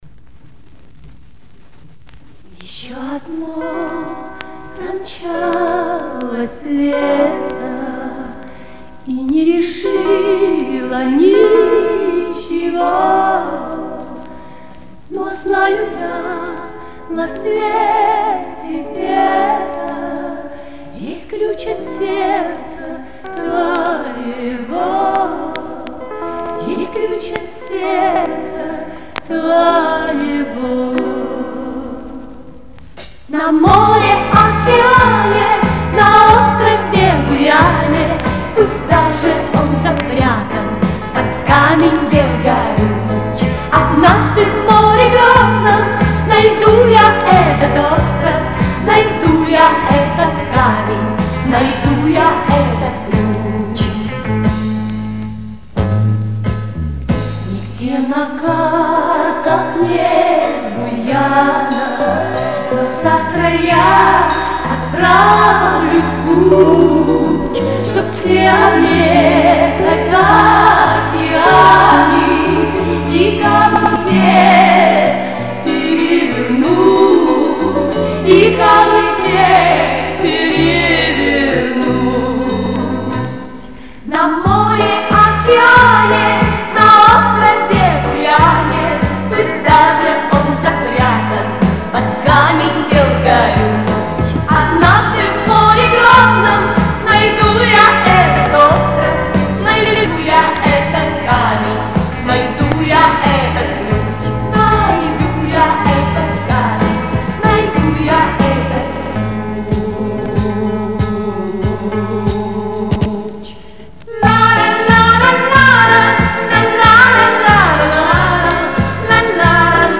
В некоторых местах заикается